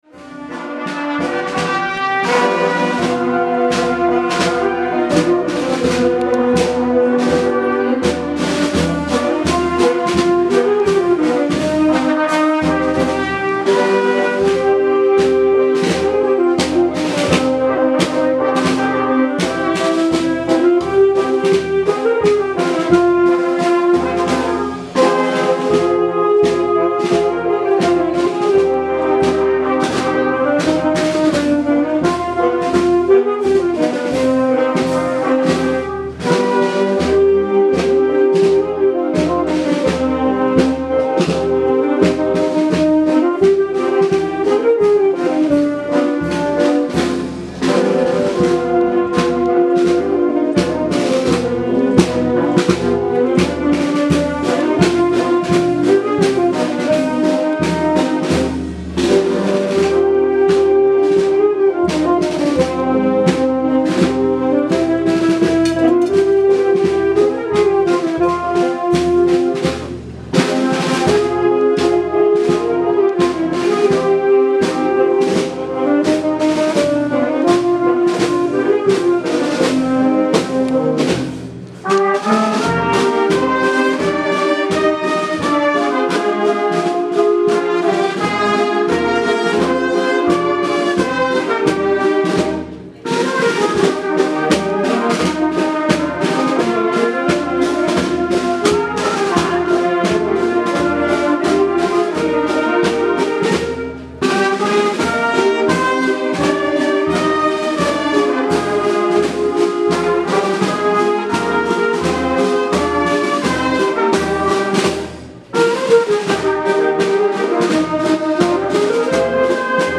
Traslado de Santa Eulalia desde la ermita de San Roque a la parroquia de Santiago 2015
Autoridades municipales, civiles y religiosas, y cientos de totaneros, acompañaron el pasado 9 de diciembre en procesión a la imagen de Santa Eulalia de Mérida desde la ermita de San Roque hasta su llegada a la parroquia de Santiago, donde permanecerá hasta el próximo día 7 de enero que regrese, de nuevo, en romería a su santuario en Sierra Espuña.
Desde primera hora de la tarde una comitiva institucional presidida por el teniente de alcalde de Totana, Andrés García, ediles de la Corporación Municipal, autoridades religiosas, miembros de la Fundación La Santa y la Agrupación Municipal de Música de Totana, han llegado a la ermita de San Roque, donde tras el encuentro con la imagen de Santa Eulalia la han acompañado en procesión hasta el templo parroquial.